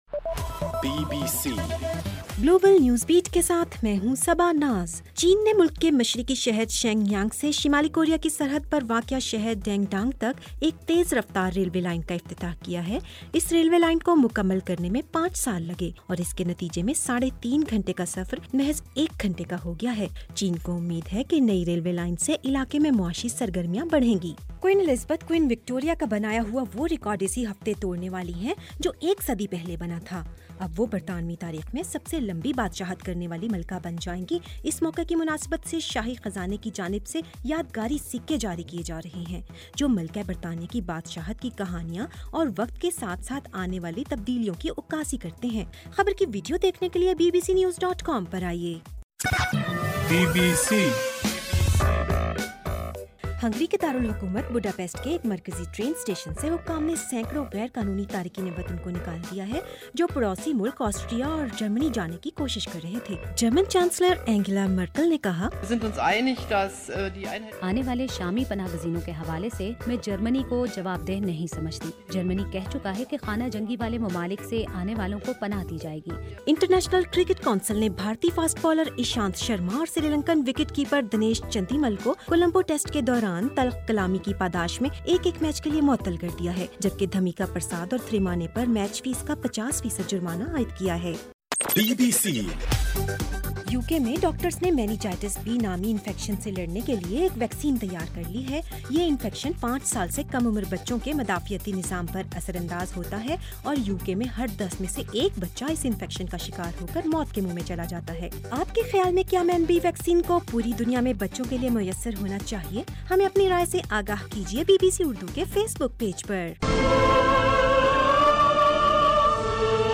ستمبر 1:رات 11 بجے کا گلوبل نیوز بیٹ بُلیٹن